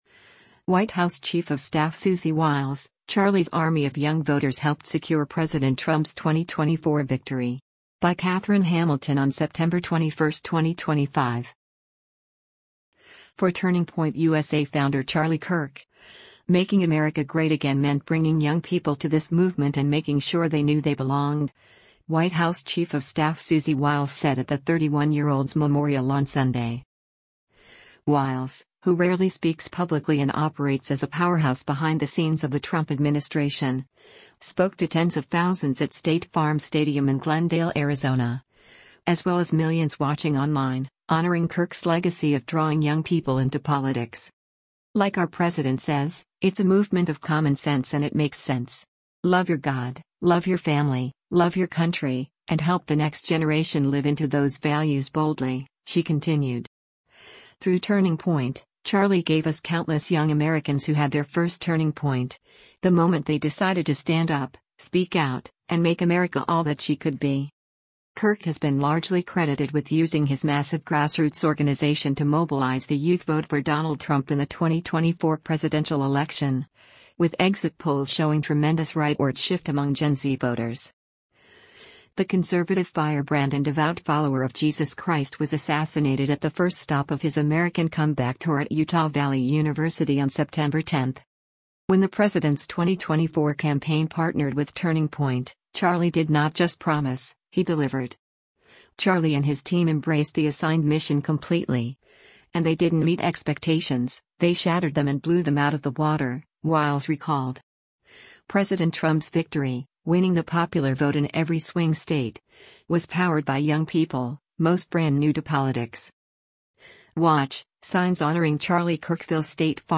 Wiles, who rarely speaks publicly and operates as a powerhouse behind the scenes of the Trump administration, spoke to tens of thousands at State Farm Stadium in Glendale, Arizona, as well as millions watching online, honoring Kirk’s legacy of drawing young people into politics.